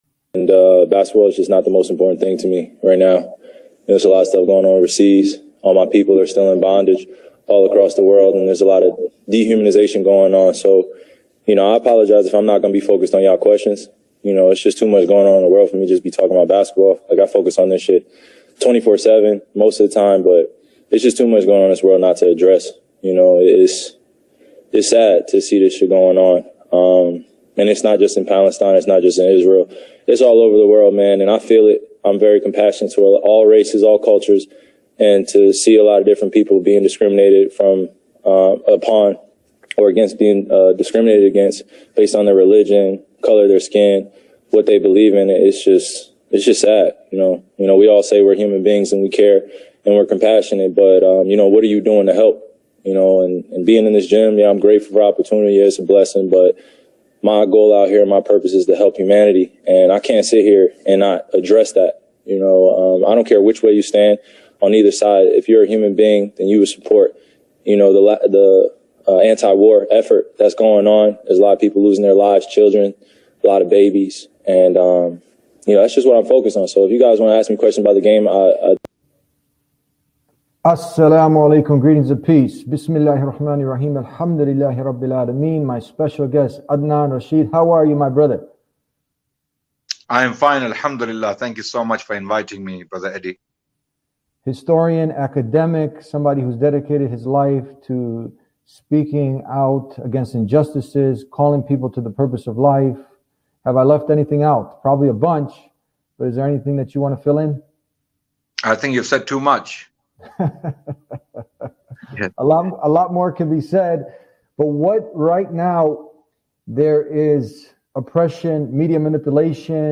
Moreover, the conversation touches on the misconception that all Muslims harbor hatred towards Jews, presenting clips of Jews who denounce Israeli apartheid and advocate for Palestinian rights.